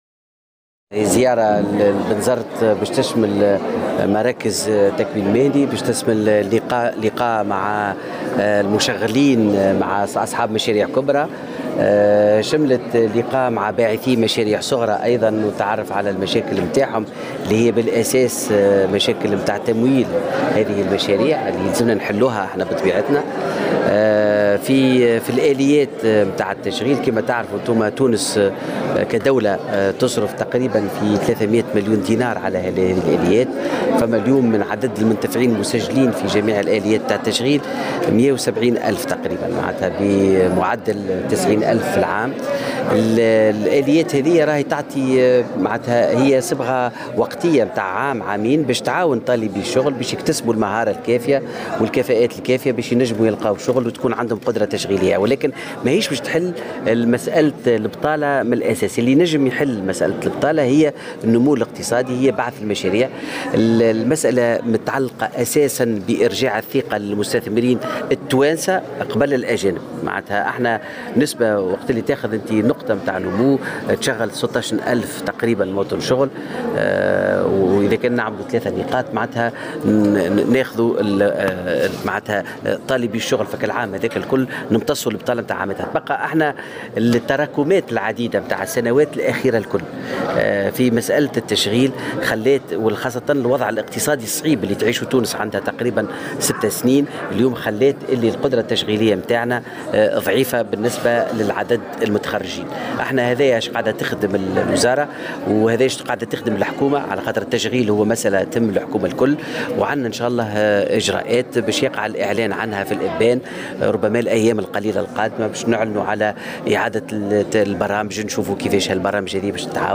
Le ministre de la Formation professionnelle et de l’Emploi, Faouzi Abderrahmane a affirmé, ce vendredi 12 janvier 2018, dans une déclaration accordée à Jawhara FM que de nouvelles mesures visant à propulser l’employabilité seront prochainement prises par le gouvernement.